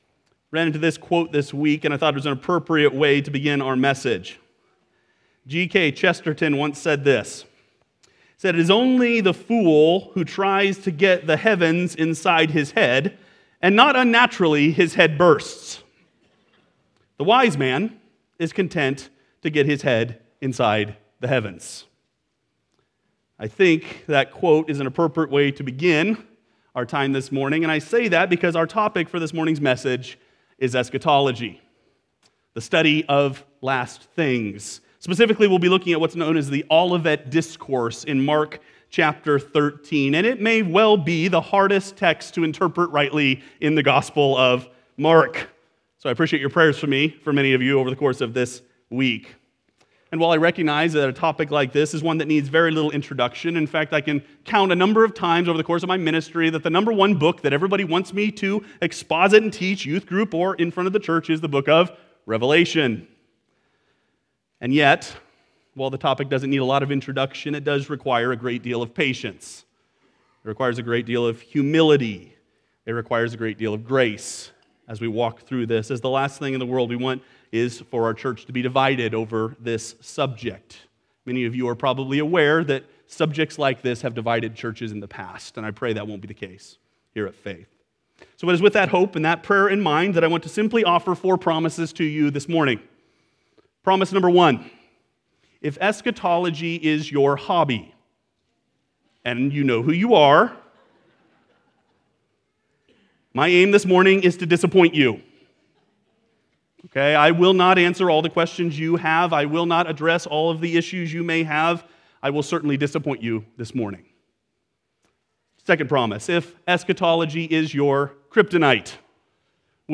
Sermons from Faith Bible Church in Lincoln, NE